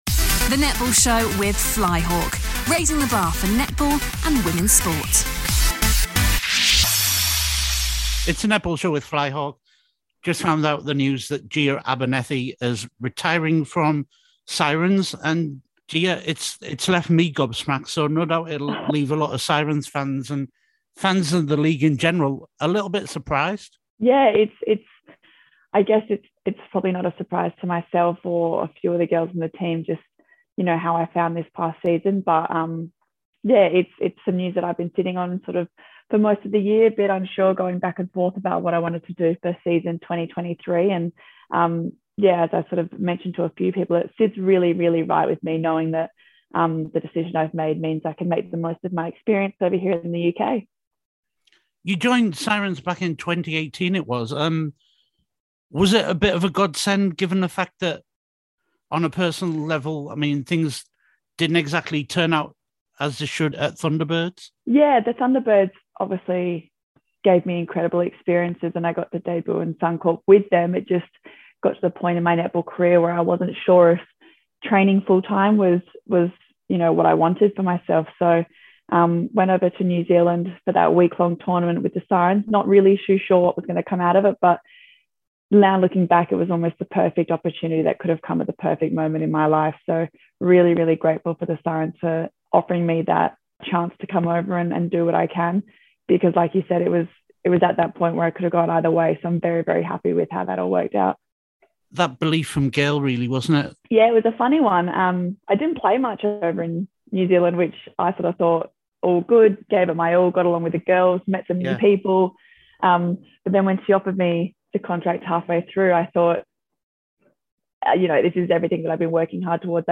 An exclusive chat